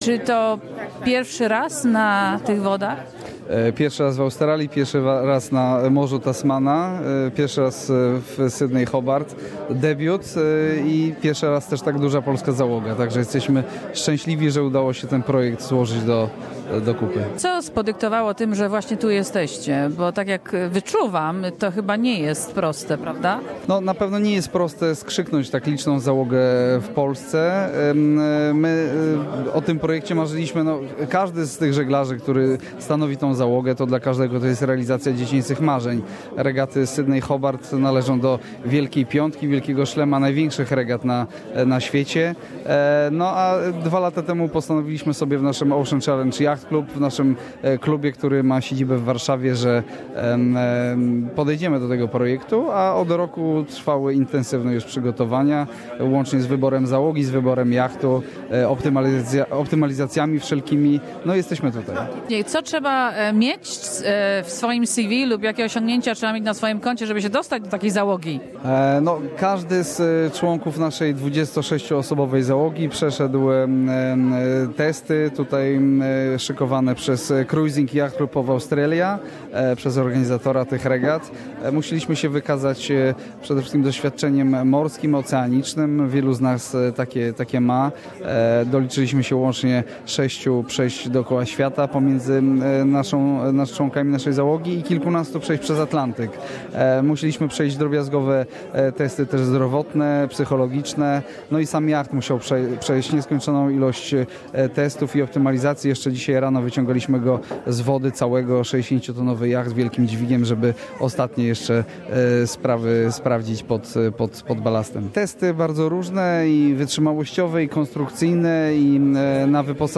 Sydney to Hobart Yacht Race starts tomorrow on Boxing Day. An interview